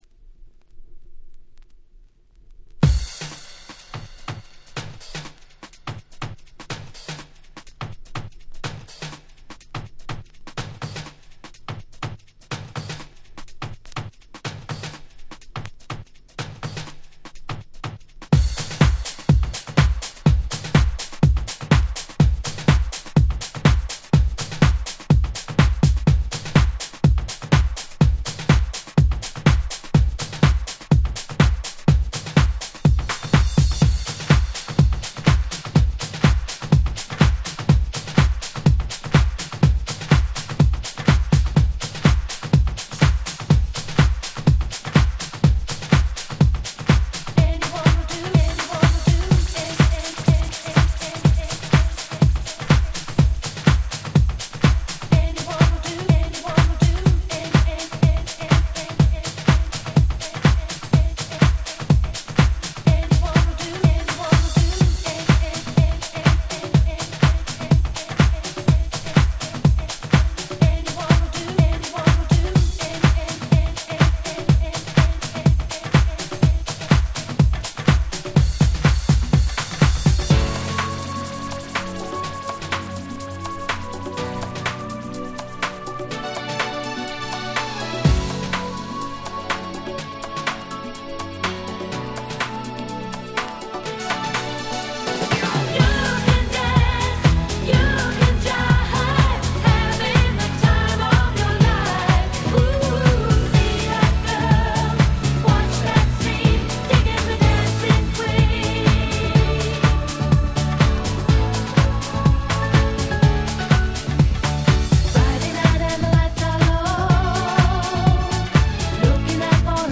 99' Nice Cover Vocal House !!
ヴォーカルハウス